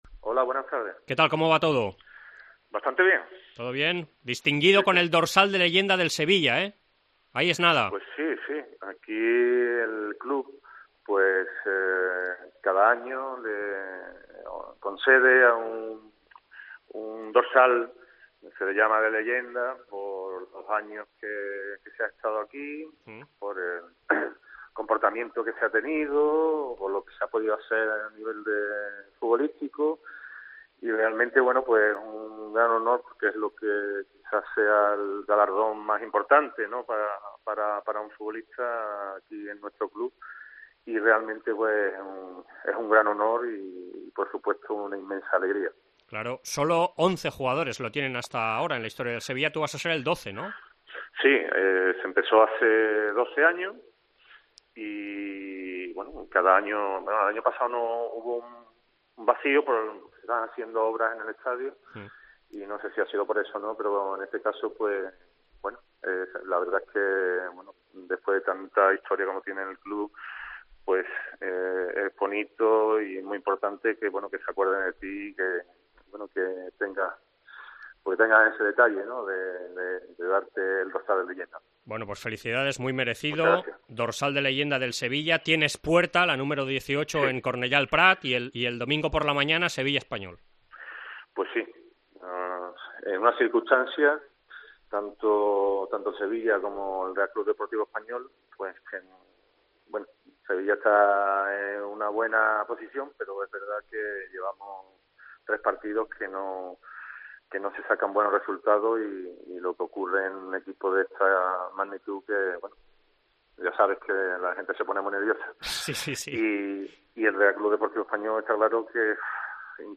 Entrevista Francisco López Alfaro, ex jugador d'Espanyol i Sevilla